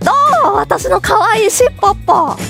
Worms speechbanks
Excellent.wav